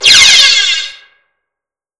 laser01_processed (pichuun basic).wav